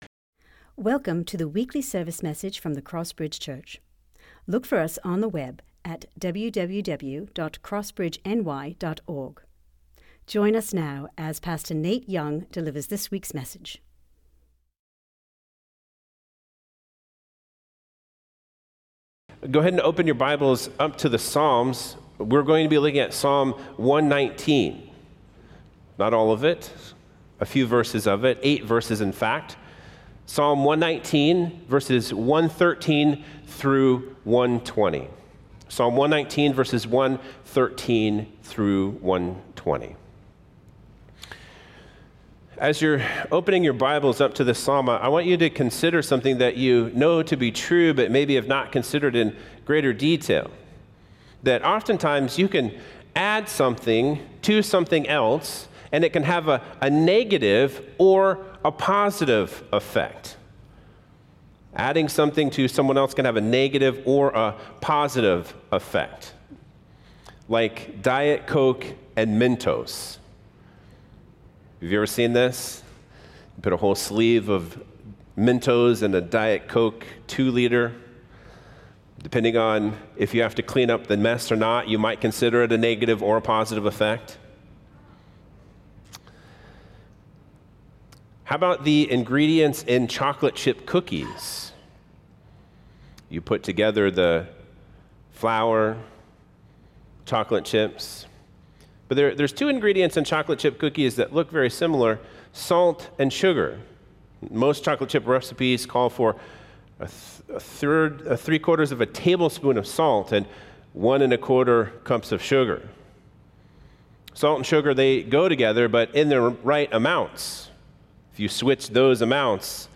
Sermons | CrossBridge Church